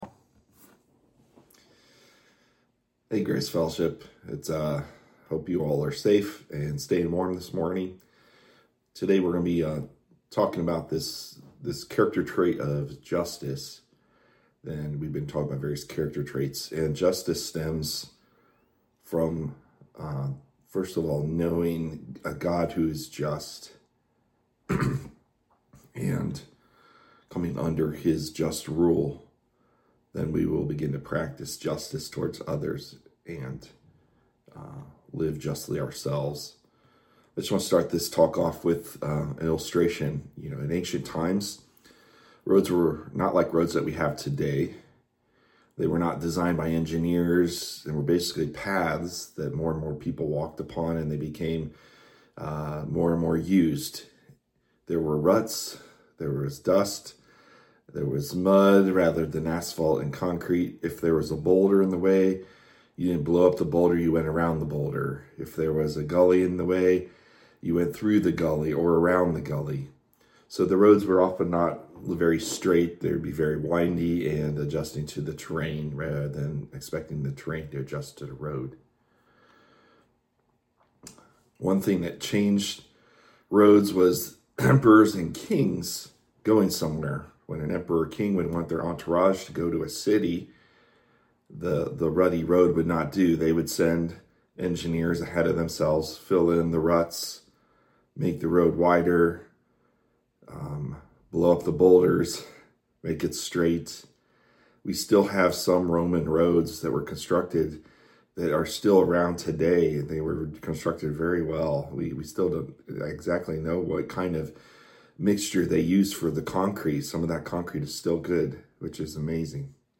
This week due to inclement weather, Grace Fellowship Church was not able to meet in person.